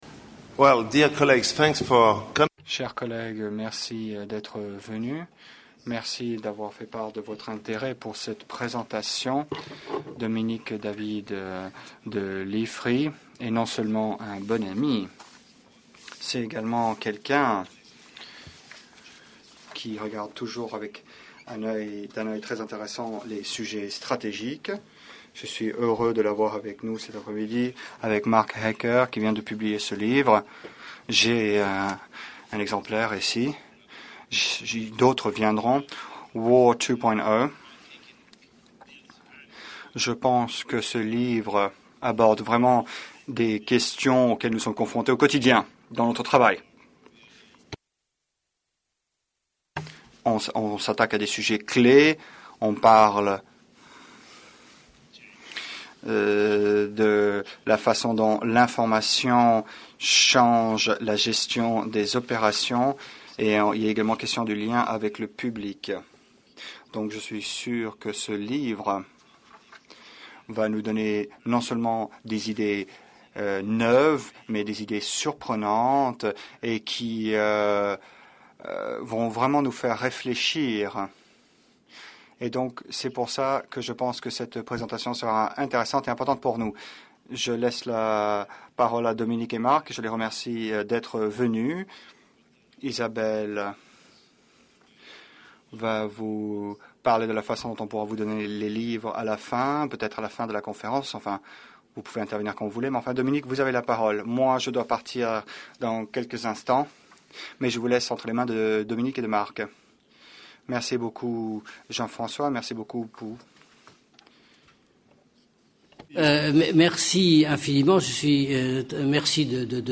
Communication in today’s conflicts : Conference in the NATO Library on 6 July
How is the expansion of the internet and, more specifically, web 2.0 social media affecting communication and the media in today’s conflicts? A short conference at NATO Headquarters on 6 July, organized by the NATO Library and the Institut français des relations internationales (IFRI), will address this question.